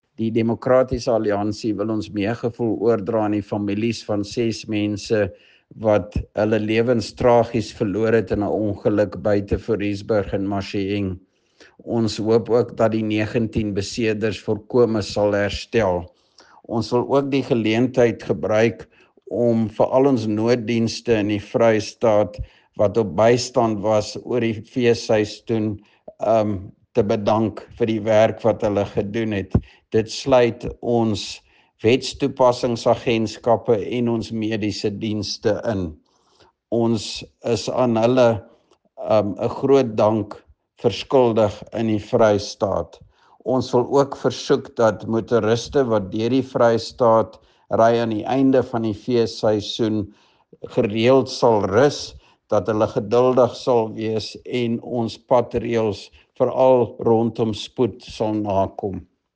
Afrikaans soundbites by Roy Jankielsohn MPL and